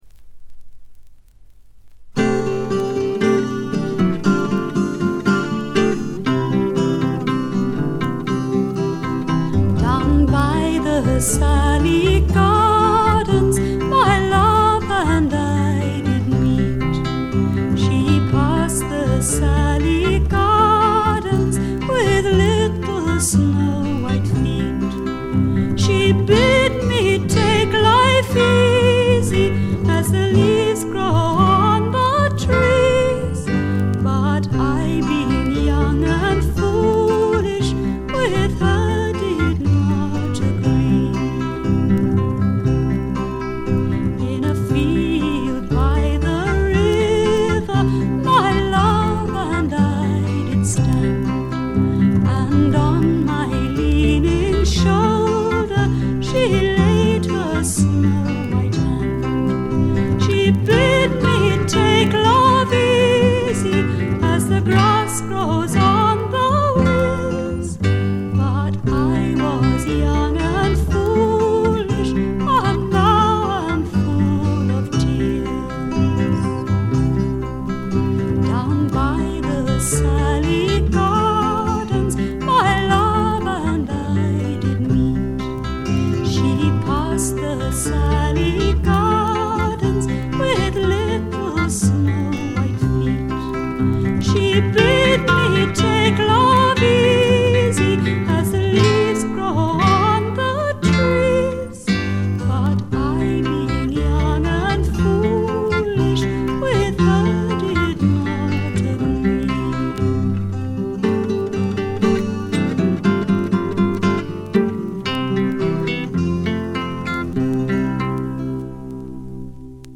軽微なバックグラウンドノイズ程度。
英国フィメール・フォークの大名作でもあります。
内容はというとほとんどがトラディショナル・ソングで、シンプルなアレンジに乗せた初々しい少女の息遣いがたまらない逸品です。
モノラル盤です。
試聴曲は現品からの取り込み音源です。